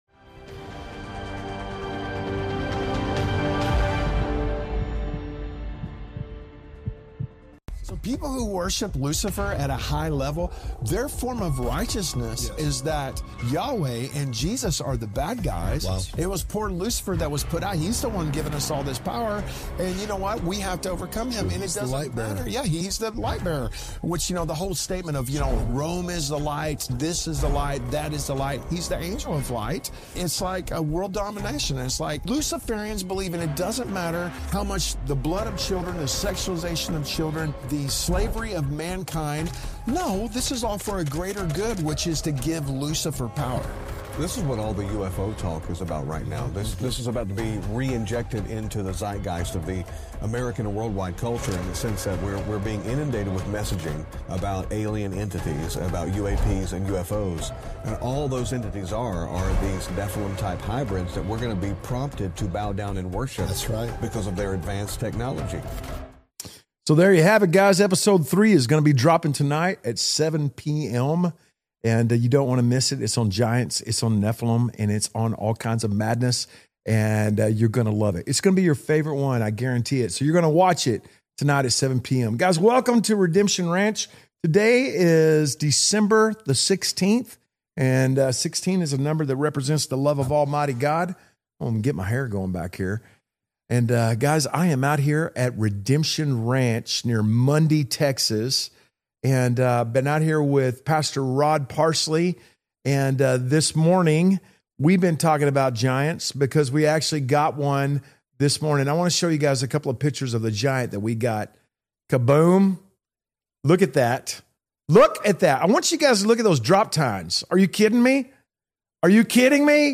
LIVE from Redemption Ranch | The Pulse - Ep 101